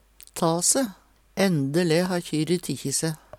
ta se - Numedalsmål (en-US)